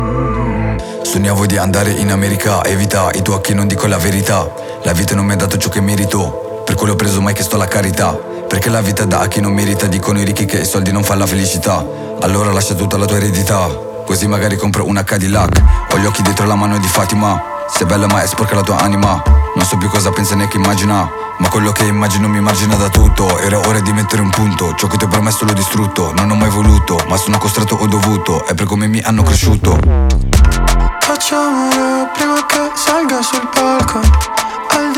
Жанр: Рэп и хип-хоп / Иностранный рэп и хип-хоп